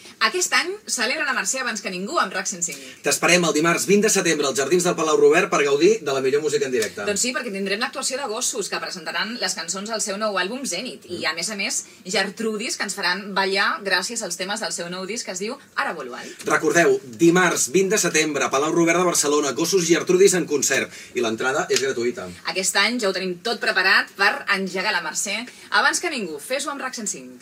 Anunci del concert de RAC 105 amb motiu de les Festes de la Mercè de Barcelona